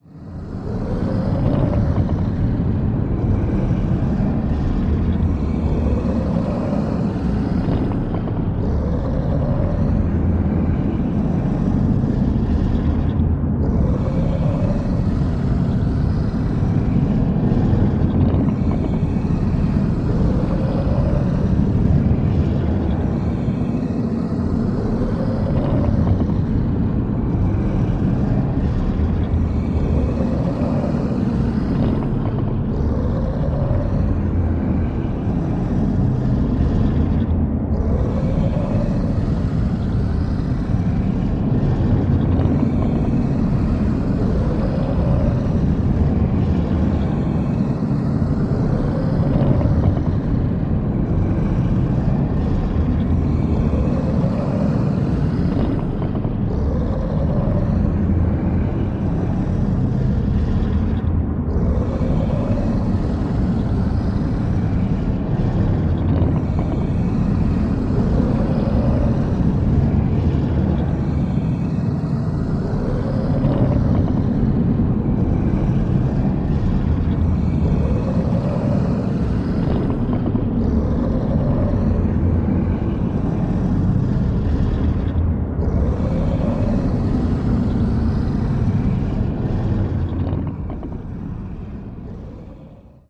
Creature Lair with Creatures